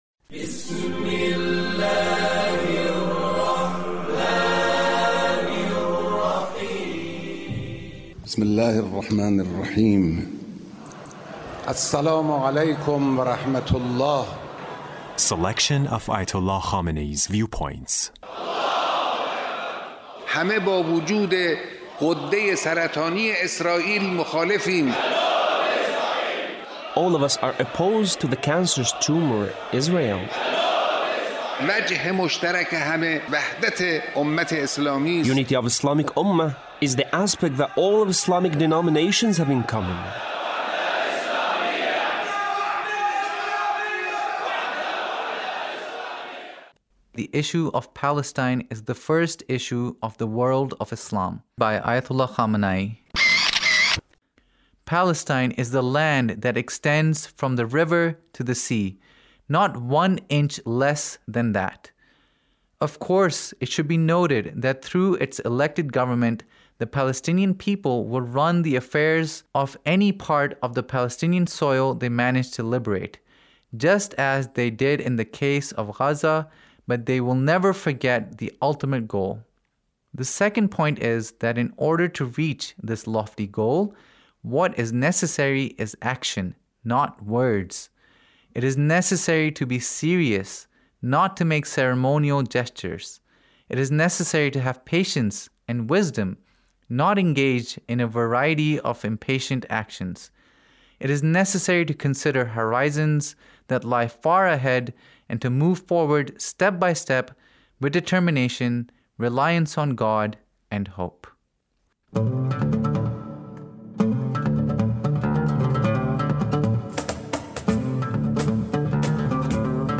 Leader's Speech (1869)